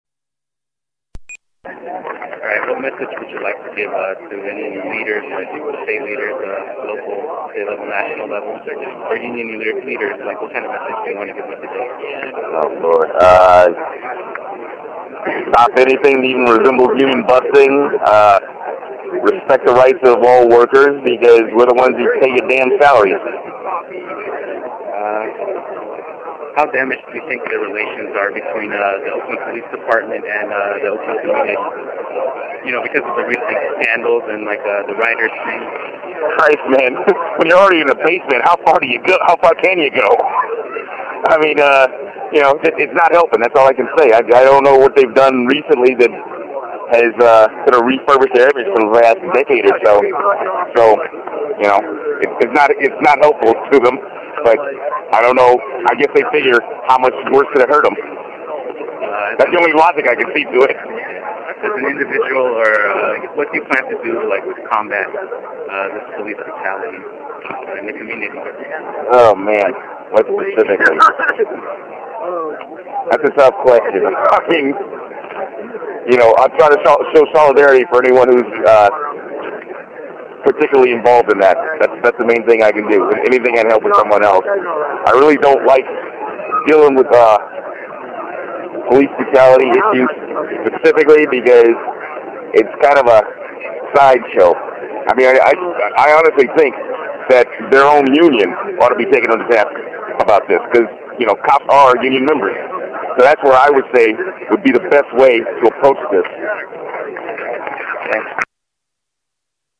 §interview
interview_at_the_union_rally.mp3